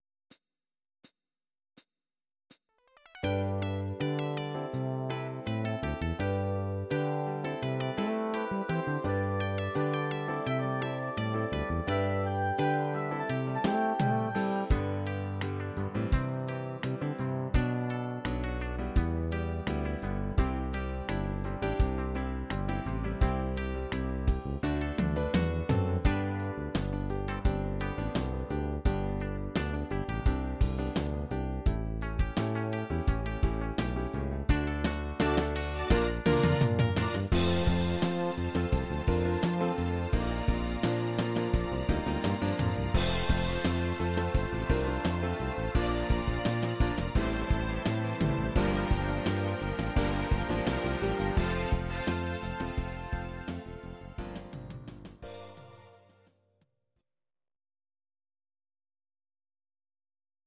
Audio Recordings based on Midi-files
Our Suggestions, Pop, 1970s